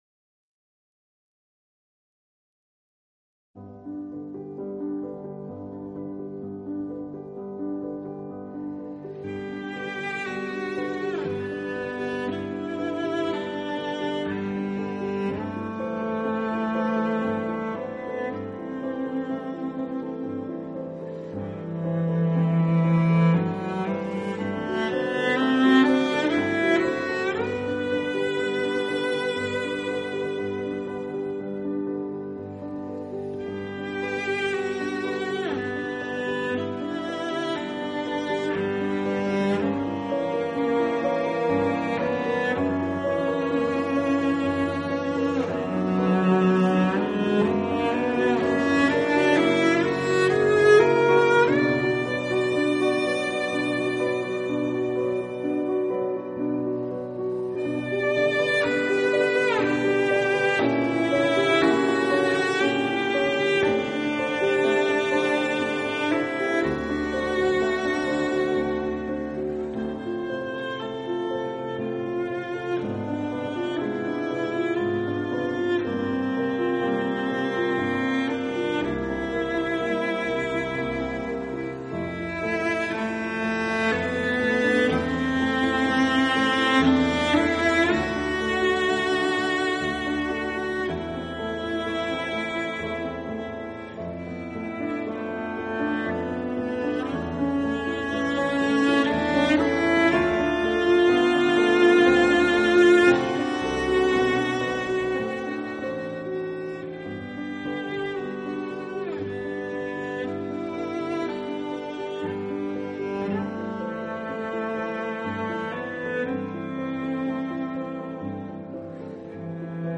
【纯音大提琴】
时其录音又极其细致、干净、层次分明，配器简洁明了，是近年唱片市场上难